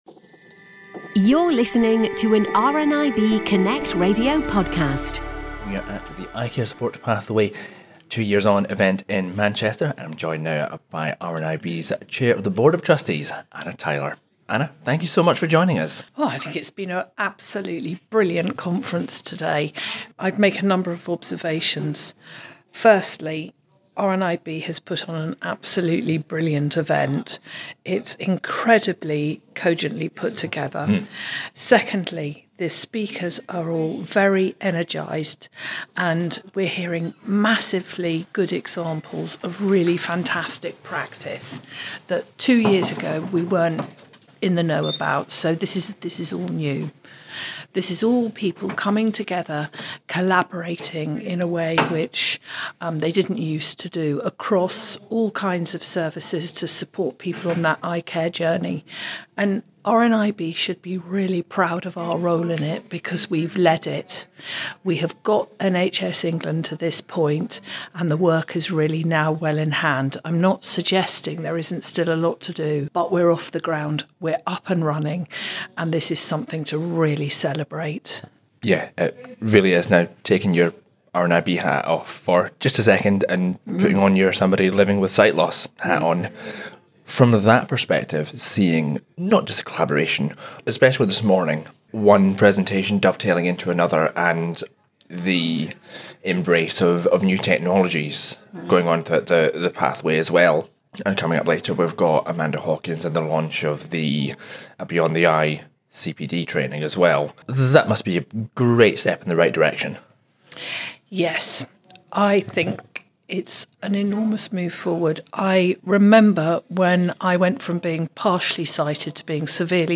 Earlier this week, RNIB have marked two years since the launch of the Eye Care Support Pathway, with an event in Manchester which brought together optometrists, sector leaders and other partners.